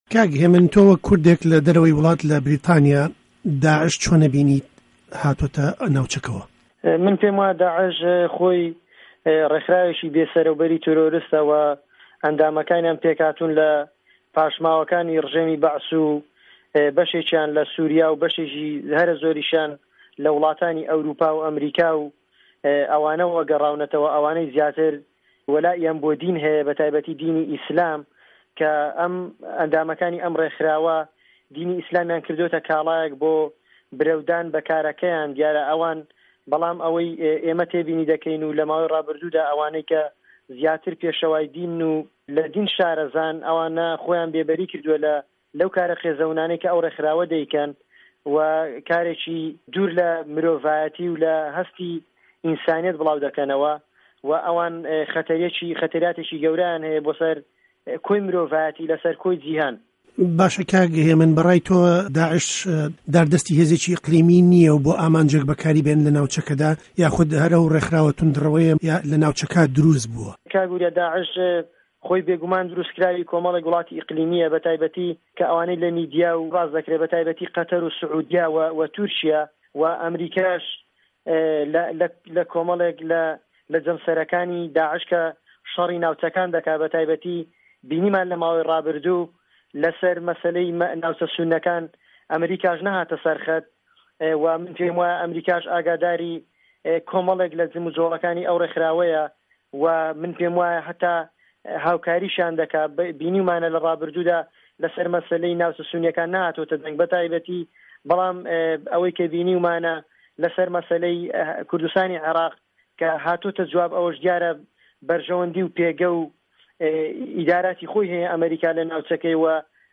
عێراق - گفتوگۆکان